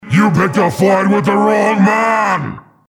This is an audio clip from the game Team Fortress 2 .
Category:Saxton Hale audio responses